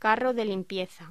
Locución: Carro de limpieza
voz